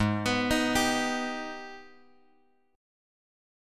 G# Minor Major 7th